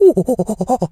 monkey_chatter_16.wav